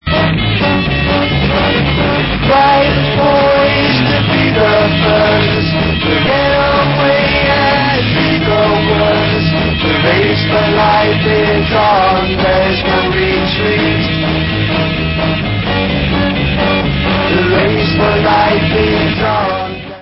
Acoustic Version
sledovat novinky v kategorii Pop